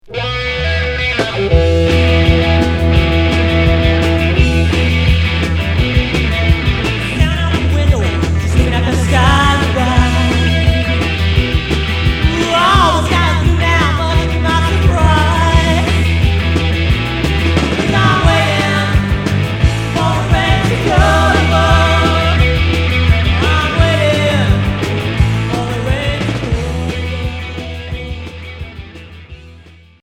Rock punk psyché Unique 45t retour à l'accueil